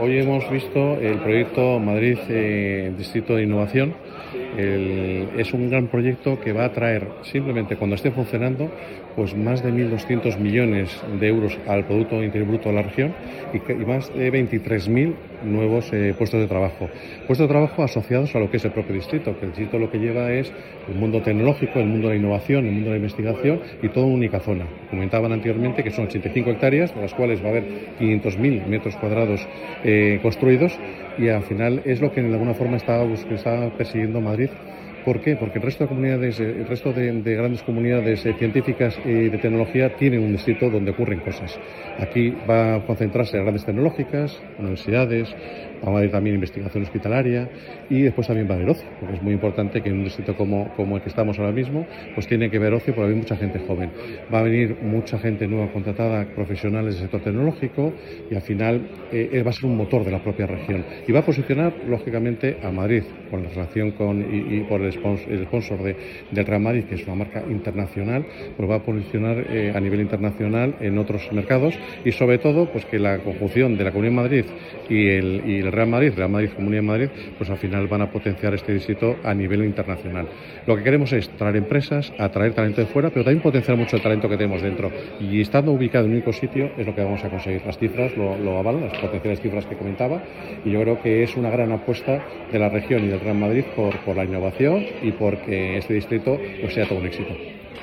Audio con declaraciones del Consejero en la página web de la C. de Madrid con la nota de prensa]